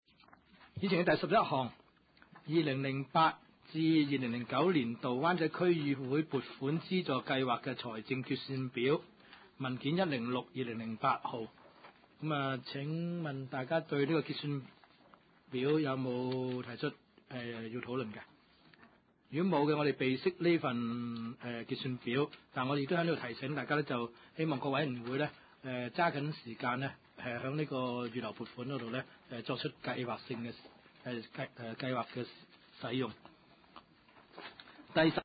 灣仔區議會第 六次會議
灣仔民政事務處區議會會議室